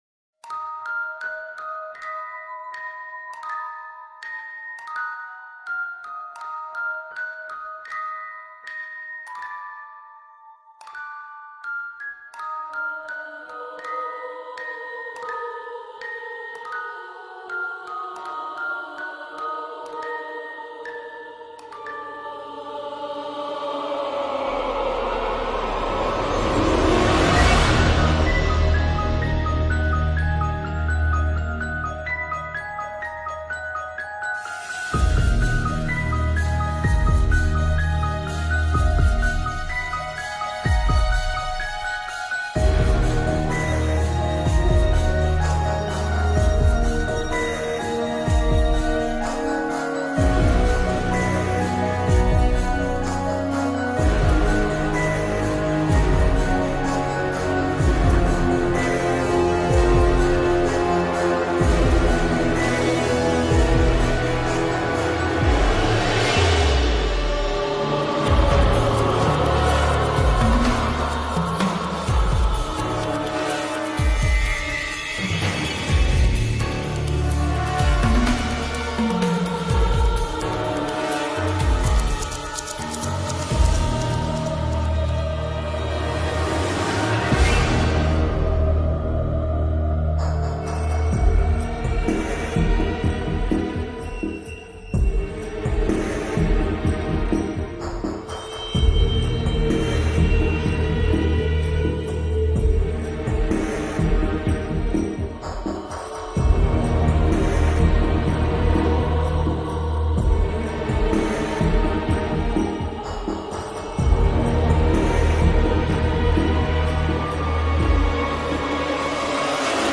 LBoiJ43mmOc_اقوي-لحن-رعب.mp3